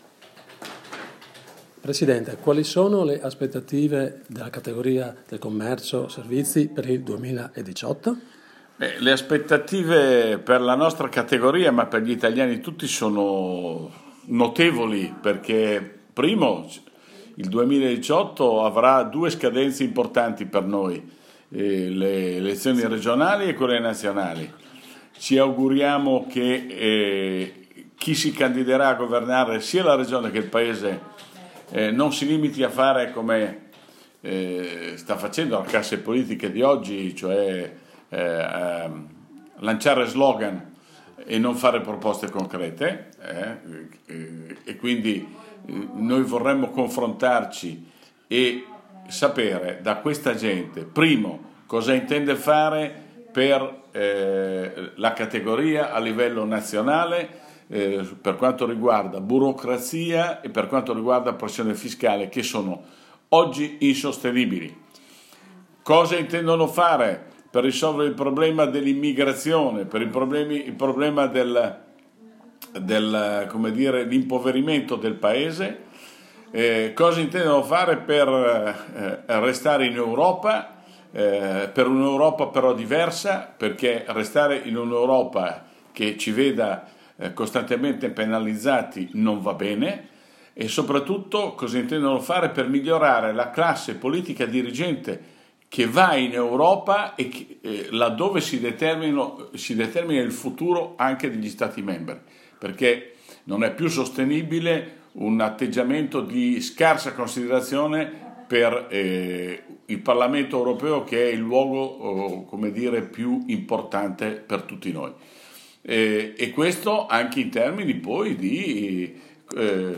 Di seguito il podcast dell’intervista